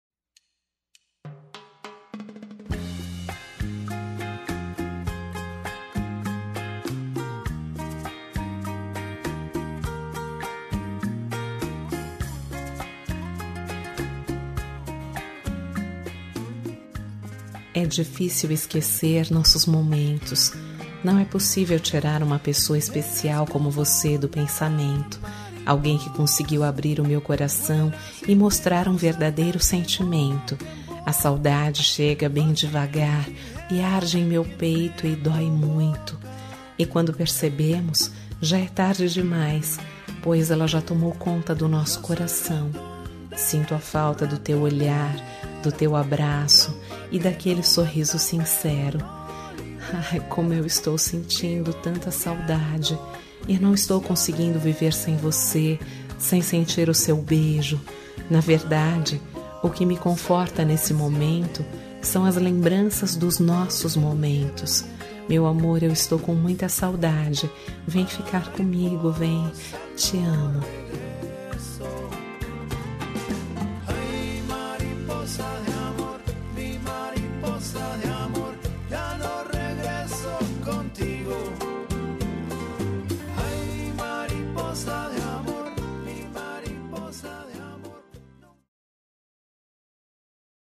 Telemensagem de Saudades – Voz Feminina – Linda – Cód: 75580